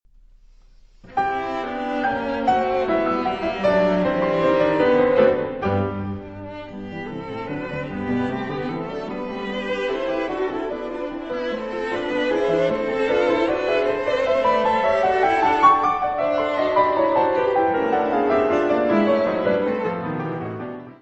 violino
violoncelo
piano
: stereo; 12 cm
Área:  Música Clássica
Trio for violin, cello and piano in G minor, op.8
Scherzo. Con moto, ma non troppo.